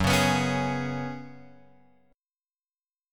F#sus2sus4 chord {2 4 x 4 2 4} chord